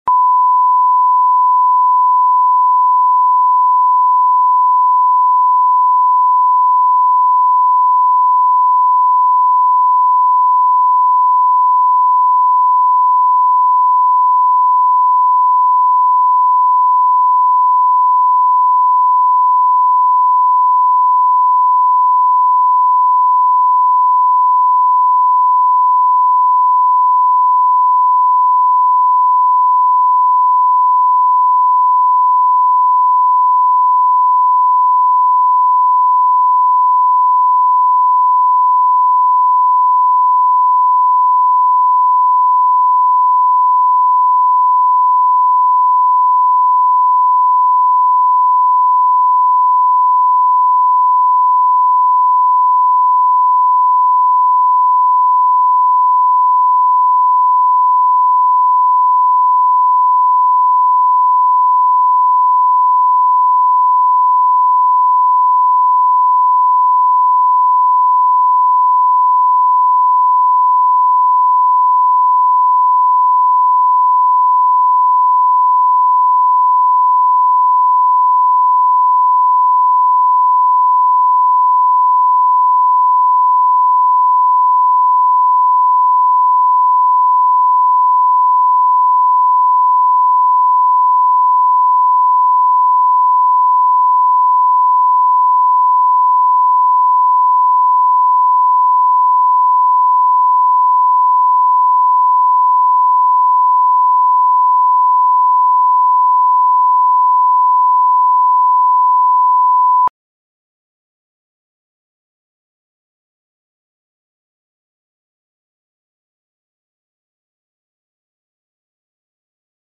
Аудиокнига Мамин любовник | Библиотека аудиокниг